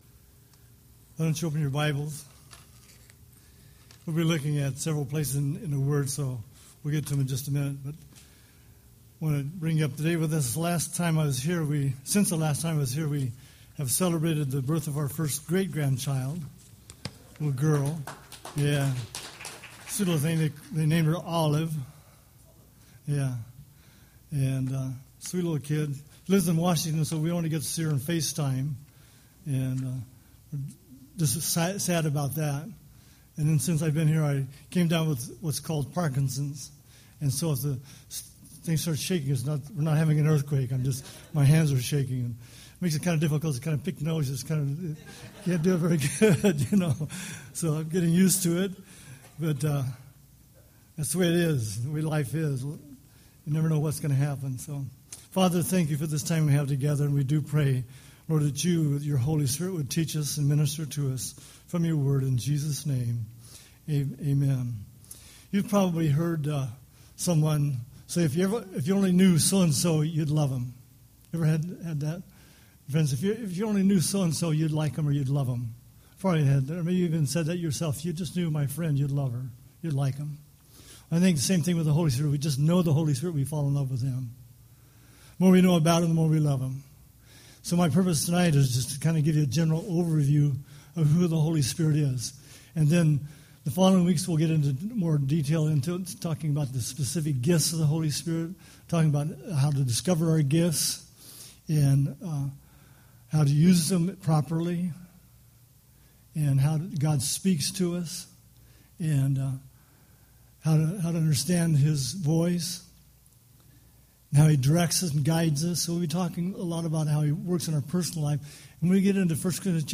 Series: Power of the Holy Spirit Service: Sunday Evening %todo_render% « Gently Leading with the Strength of the Gospel Avoid!